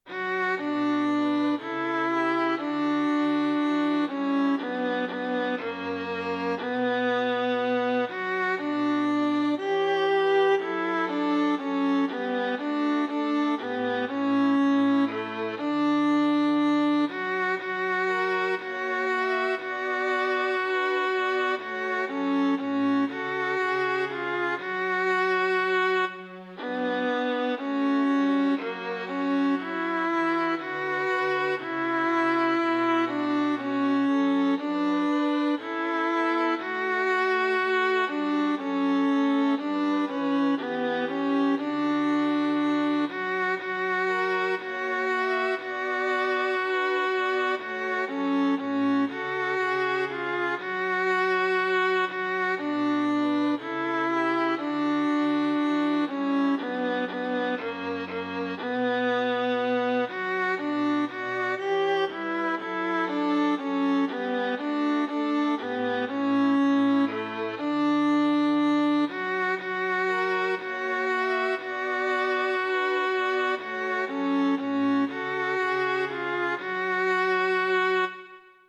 Klik på linket 'Alt øve', 'Tenor øve' etc. for at høre korsatsen med fremhævet understemme.
SAB korsats bygget over egen melodi 2008
Alt øve